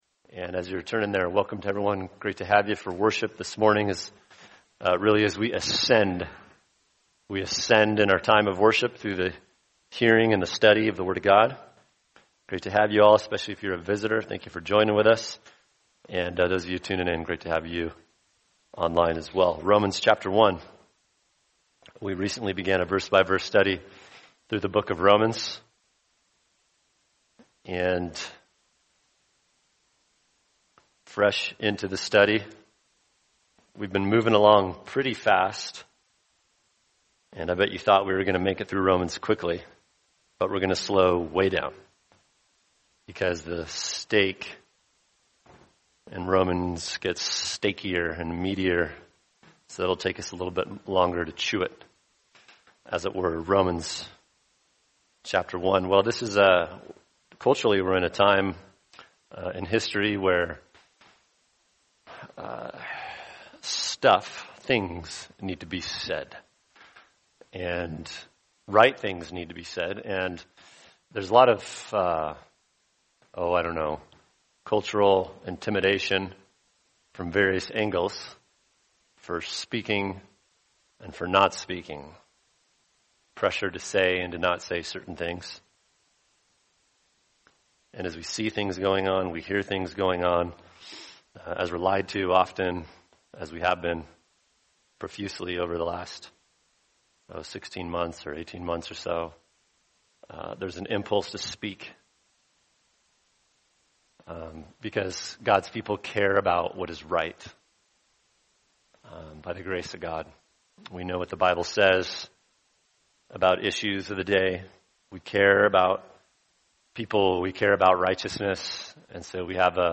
[sermon] Romans 1:14-15 Evangelism Reminders For Every Christian | Cornerstone Church - Jackson Hole